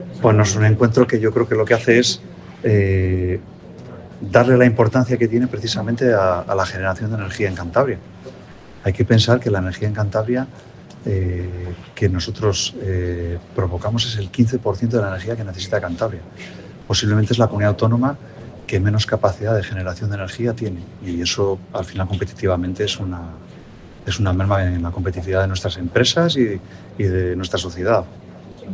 Jornada Energía en Cantabria, situación actual y renovables - CEOE-CEPYME